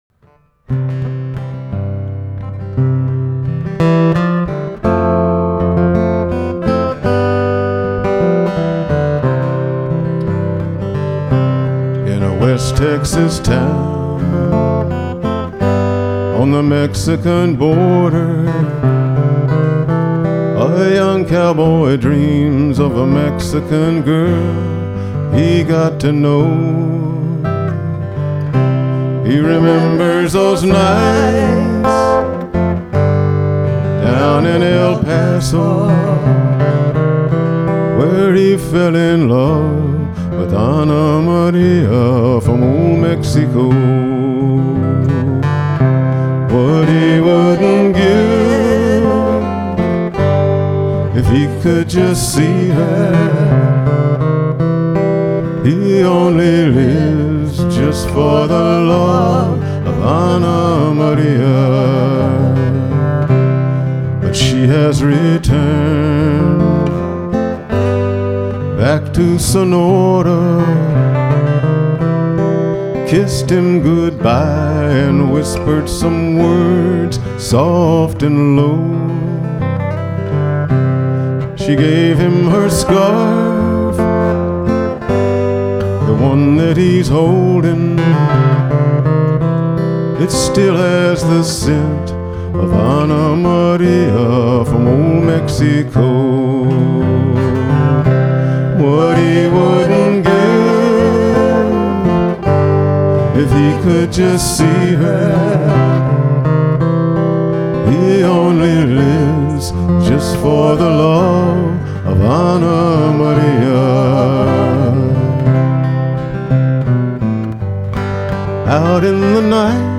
It's the border ballad